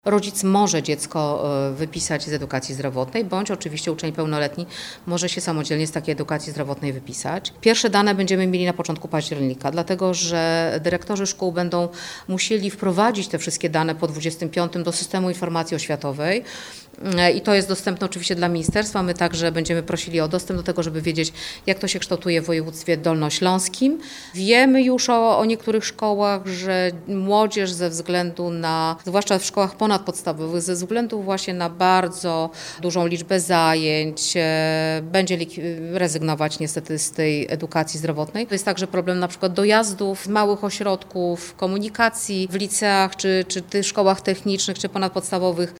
Pierwsze dane będziemy mieć na początku października – mówi Dolnośląska Kurator Oświaty, Ewa Skrzywanek.